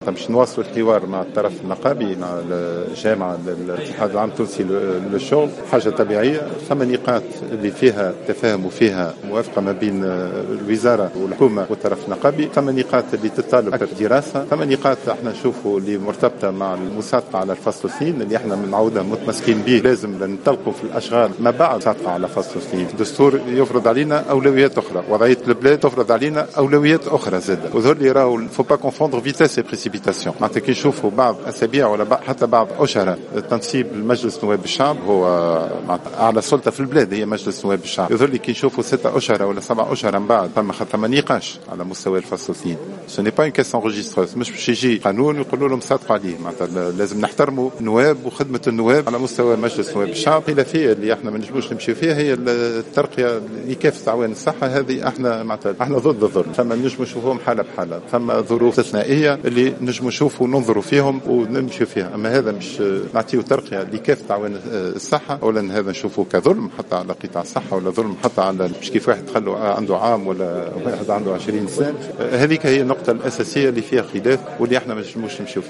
أعلن وزيرُ الصحّة سعيد العايدي خلال ندوة صحفية عقدت اليوم الأربعاء 08 جويلية 2015 عن تقدم المفاوضات مع الجامعة العامة للصحّة.